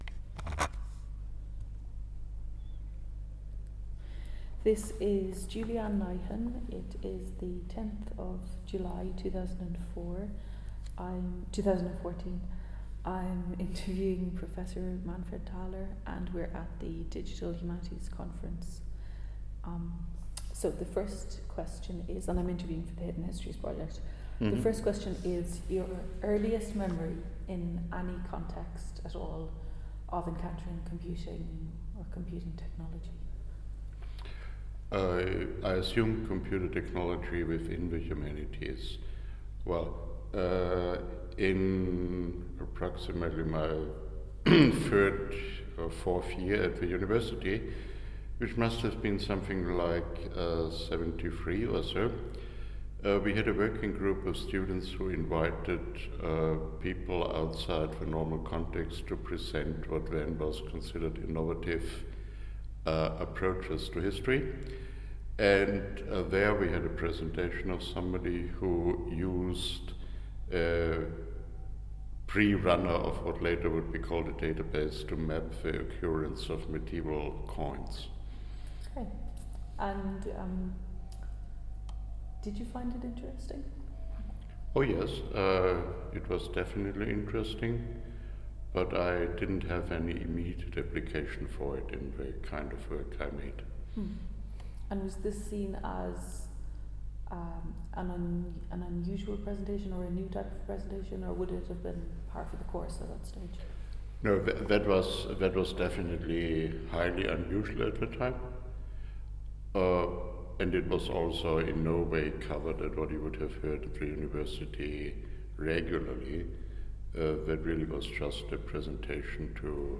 Language English Part of Series Computation and the Humanities interviews fileFormat mp3 Title It's probably the only modestly widely used system with a command language in Latin Interview Summary This interview was carried out on 10 July 2014 at the Digital Humanities Conference in Lausanne, Switzerland.
Subject An oral history interview on the history of Digital Humanities for the Hidden Histories project Processed Derivative Material Full text in Chapter 13 of Computation and the Humanities Rights Interview audio files are made available under a creative commons licence “by-nc-nd”